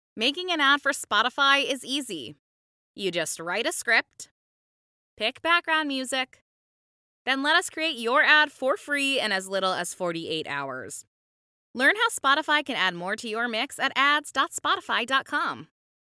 Female
My voice is warm, friendly, and bright.
Radio Commercials
Spotify Spot (Raw)